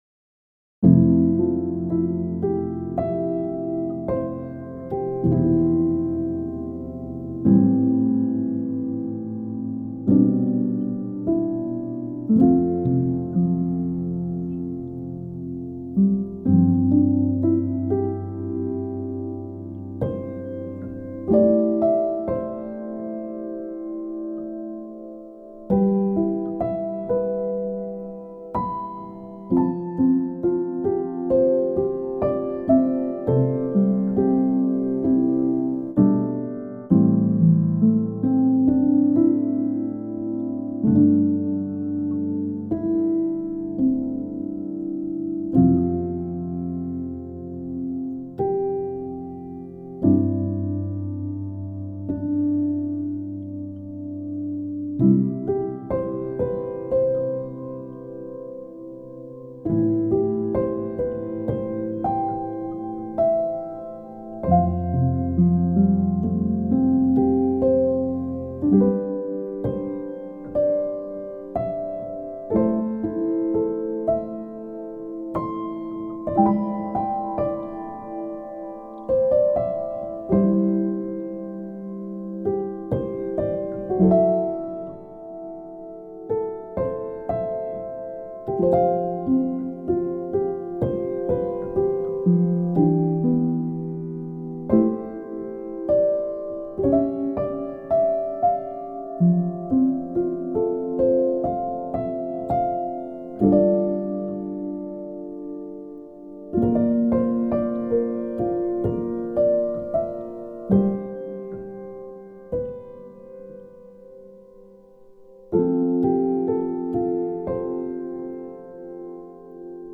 未分類 ゆらぎの中 ピアノ 寝落ち 穏やか 音楽日記 よかったらシェアしてね！